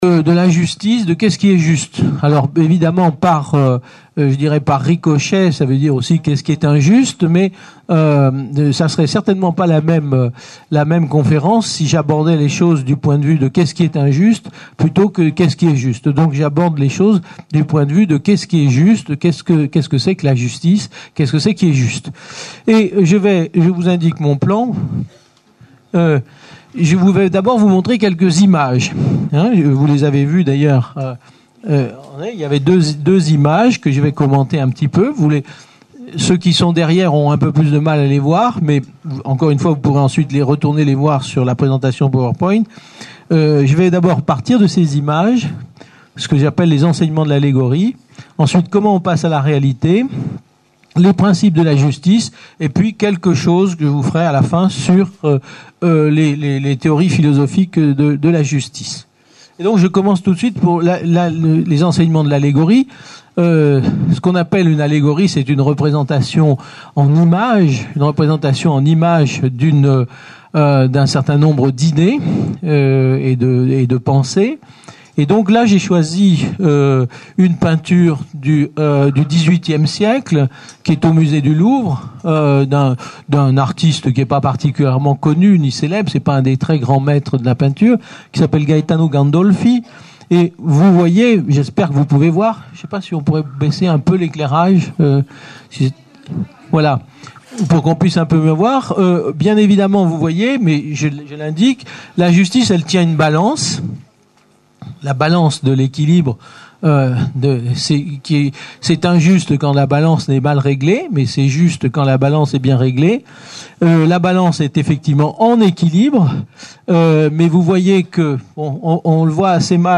Une conférence de l'UTLS au Lycée La justice, qu'est ce que c'est ? par Yves Michaud Lycée Eugène Ionesco ( 92 Issy )